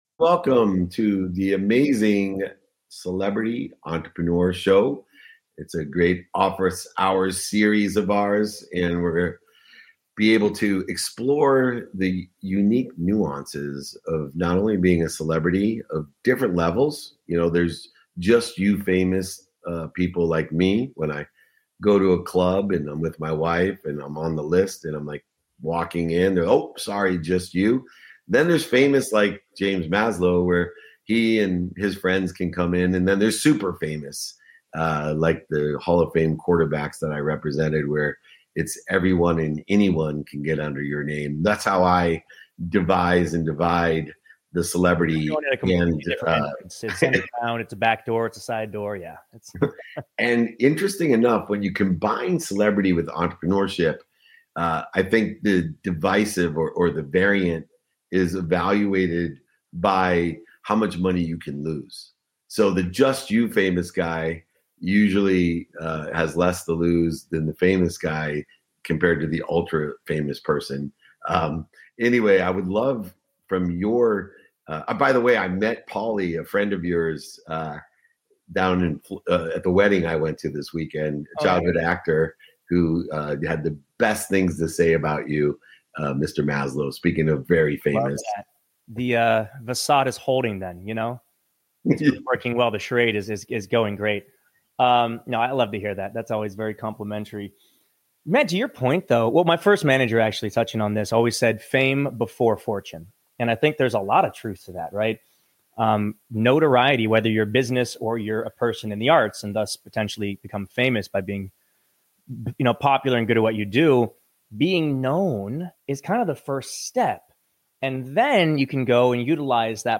In today’s episode, I sit down with actor, musician, and entrepreneur James Maslow to talk about what it really takes to build a sustainable career in the spotlight.